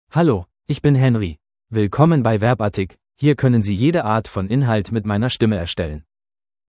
MaleGerman (Germany)
HenryMale German AI voice
Henry is a male AI voice for German (Germany).
Voice sample
Henry delivers clear pronunciation with authentic Germany German intonation, making your content sound professionally produced.